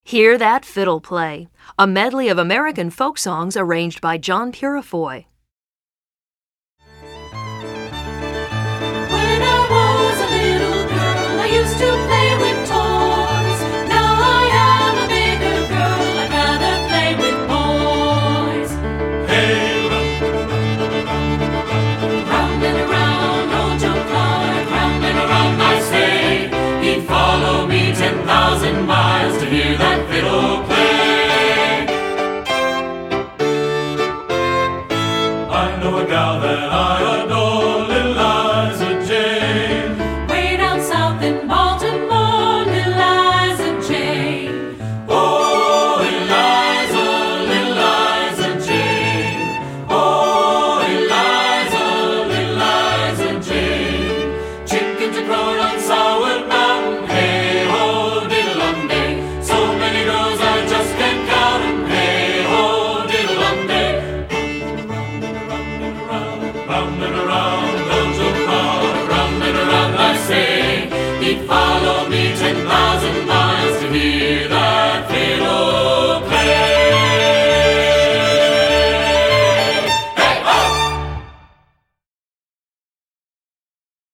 A Medley of American Folk Songs
Voicing: 2-Part